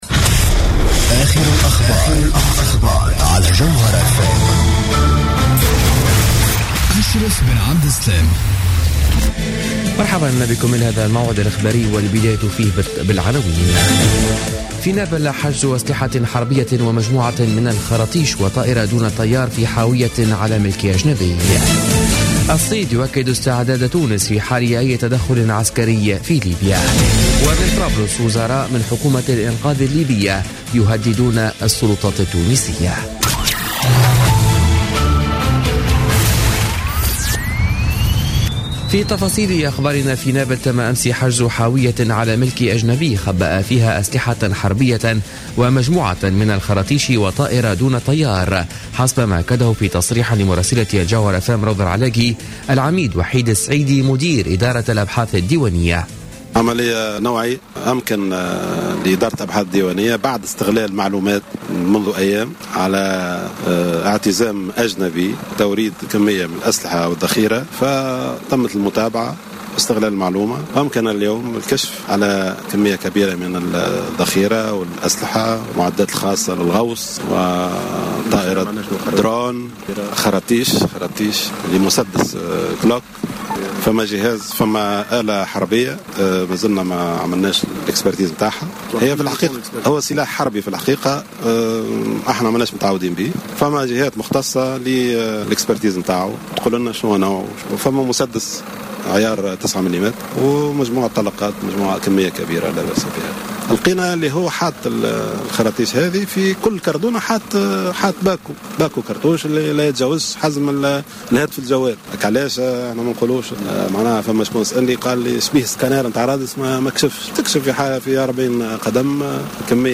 Journal Info 00h00 du mercredi 10 février 2016